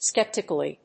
音節skep・ti・cal・ly 発音記号・読み方
/ˈskɛptɪkʌli(米国英語), ˈskeptɪkʌli:(英国英語)/